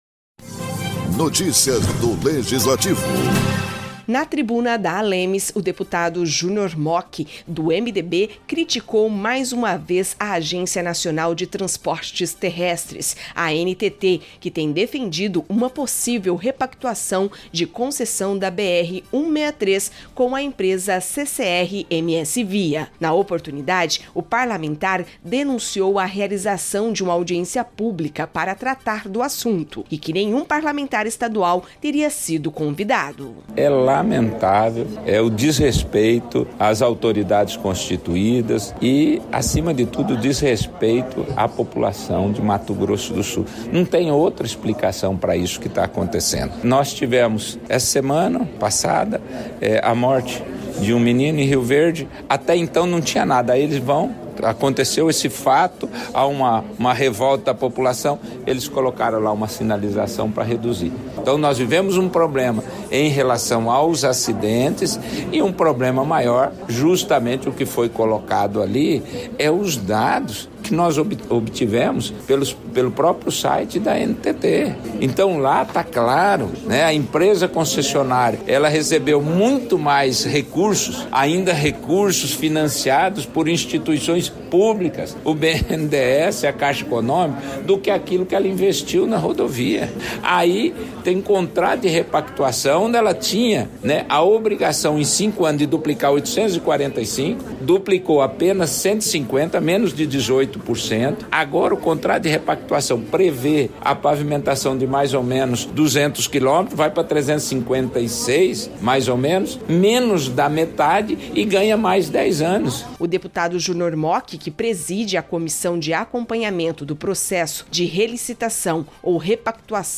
Na tribuna da Assembleia Legislativa de Mato Grosso do Sul (ALEMS), o deputado Junior Mochi (MDB) denunciou a realização de uma audiência públicapor parte da Agência Nacional de Transportes Terrestres (ANTT), com objetivo de tratar de uma possível repactuação de concessão da BR-163 com a empresa CCR MSVia.